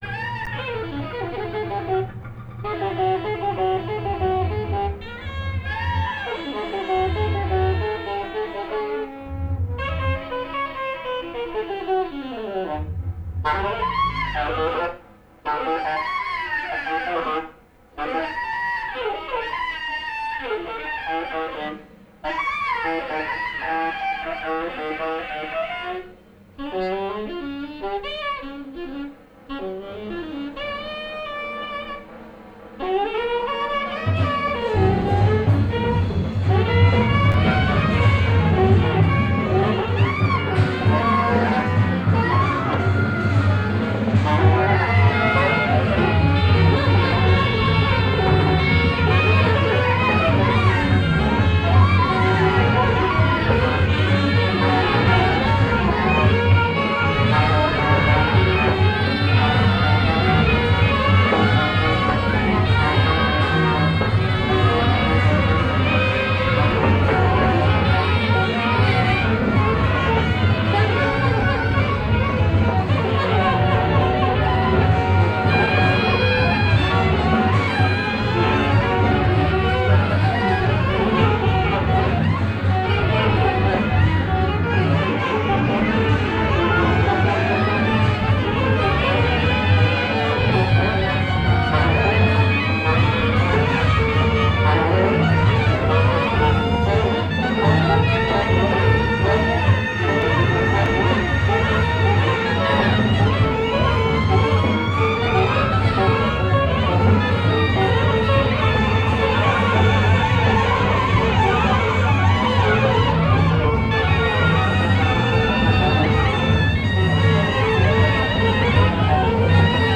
cassette
a recording of a 1991 concert